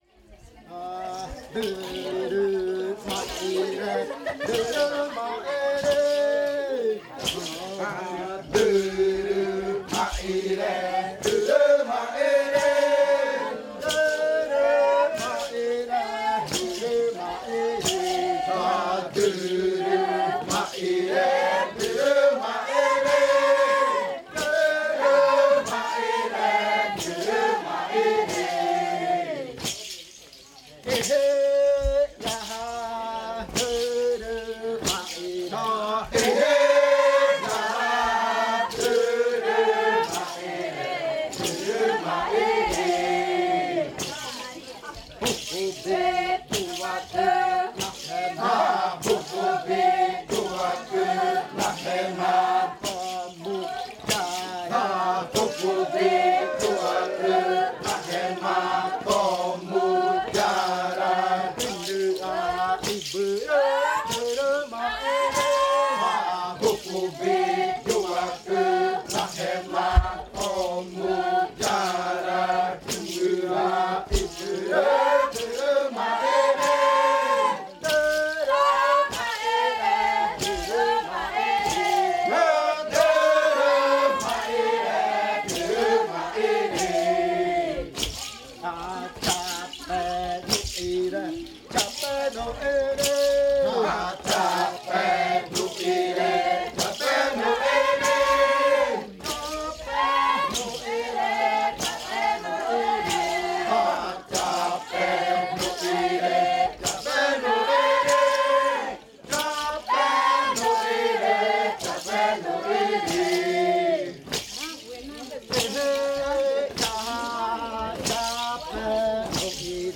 Canto después de medianoche de la variante muruikɨ
Leticia, Amazonas
con el grupo de cantores bailando en la Casa Hija Eetane.
with the group of singers dancing at Casa Hija Eetane.